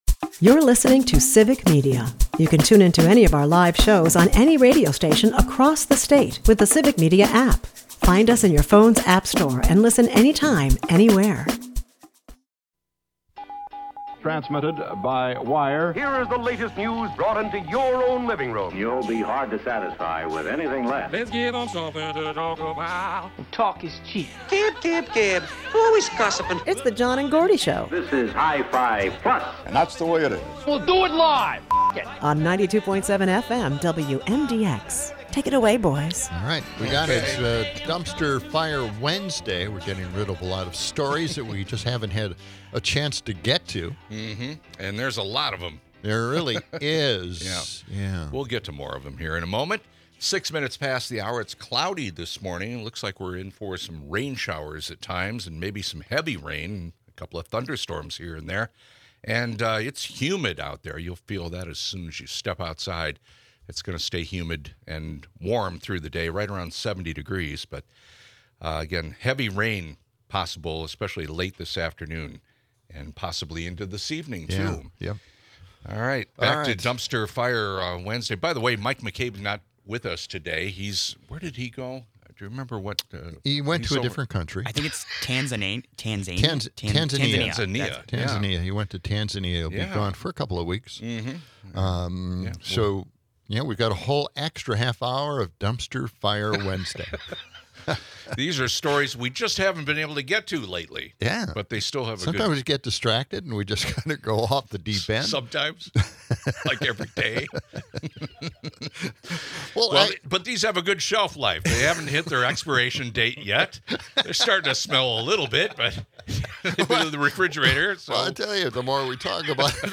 Listeners weigh in on immigration chaos, dairy farm labor struggles, and constitutional rights!